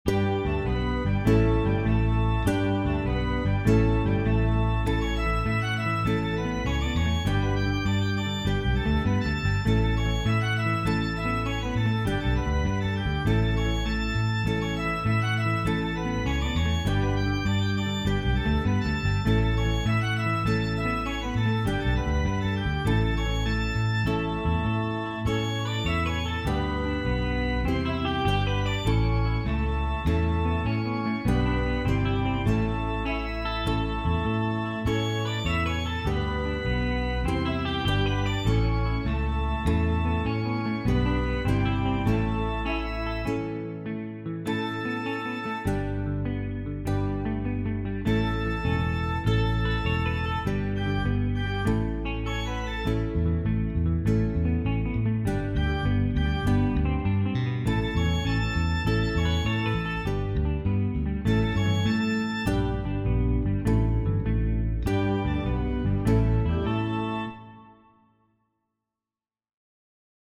This represents literally decades of guitar foolery.